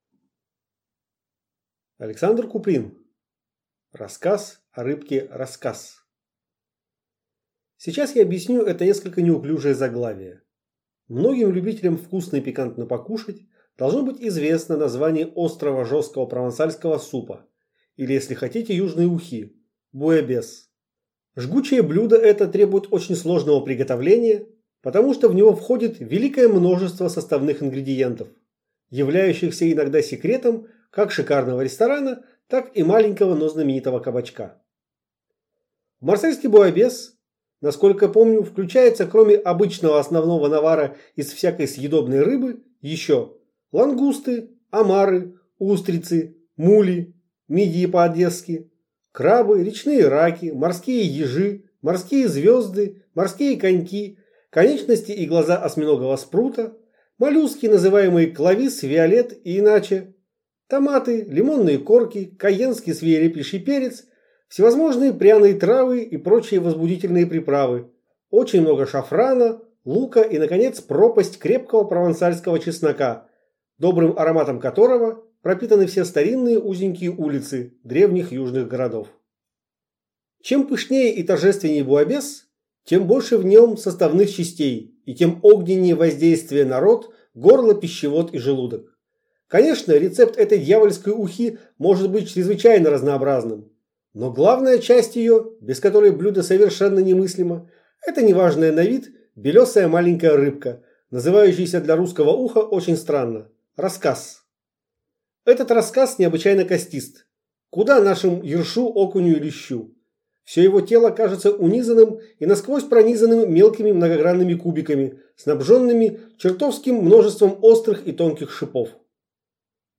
Аудиокнига Рассказ о рыбке «раскасс» | Библиотека аудиокниг